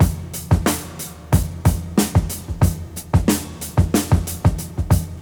• 122 Bpm Modern Drum Loop F Key.wav
Free drum groove - kick tuned to the F note. Loudest frequency: 3197Hz
122-bpm-modern-drum-loop-f-key-tiS.wav